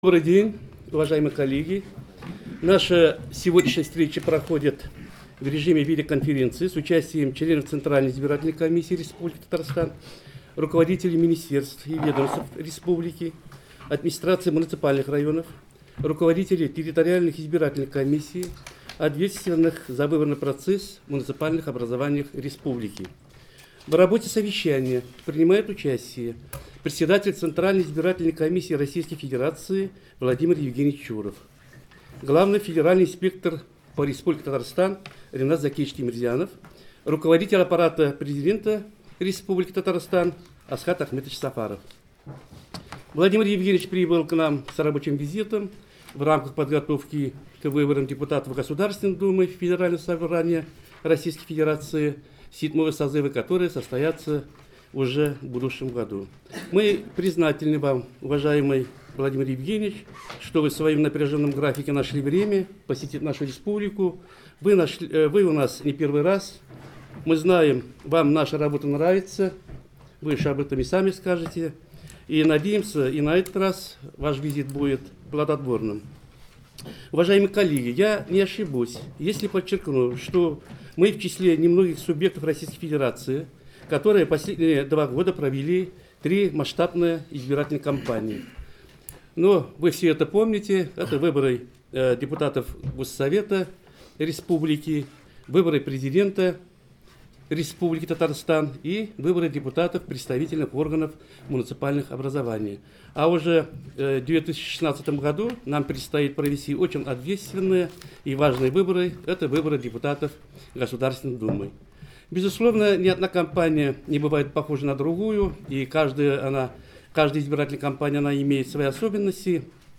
Председатель ЦИК России В.Е.Чуров принял участие в совещании ЦИК Республики Татарстан